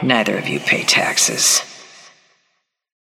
Haze voice line - Neither of you pay taxes.
Haze_match_start_haze_krill_convo01_04.mp3